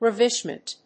音節rav･ish･ment発音記号・読み方rǽvɪʃmənt
発音記号
• / ‐mənt(米国英語)